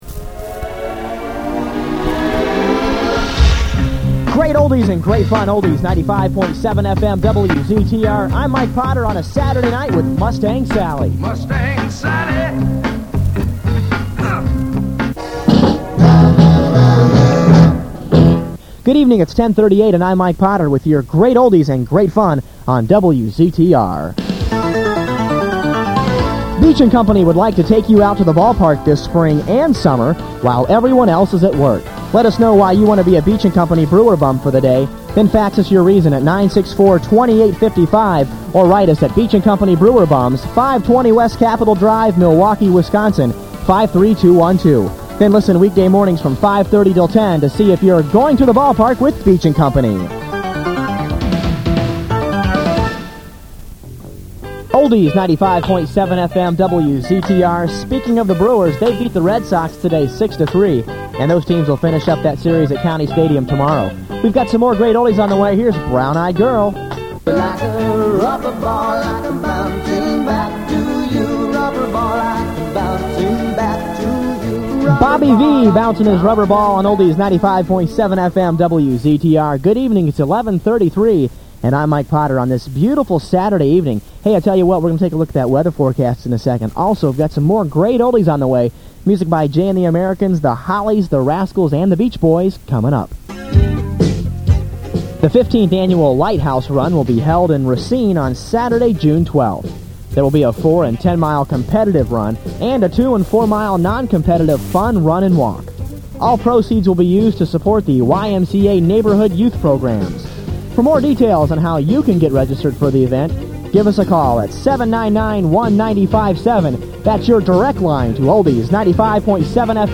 I hosted a radio show on this Albuquerque station.